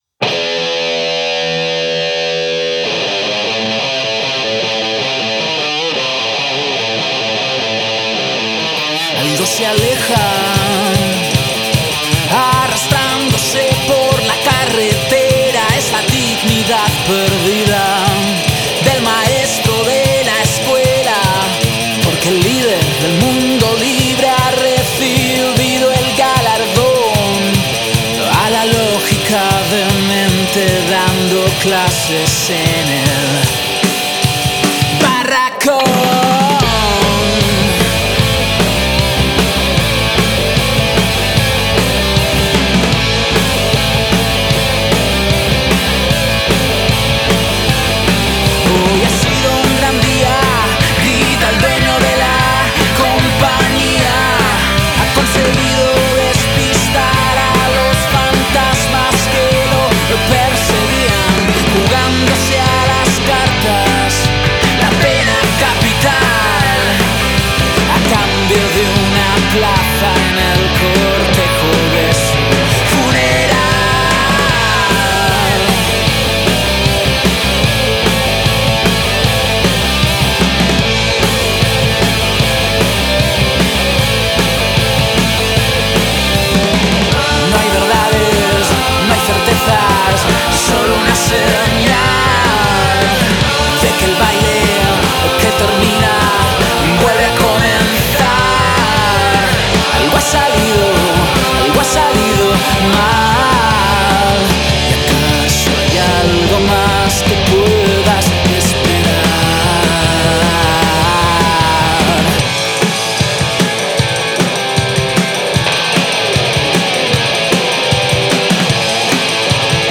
teclados y bajo
guitarra
voz y guitarra
batería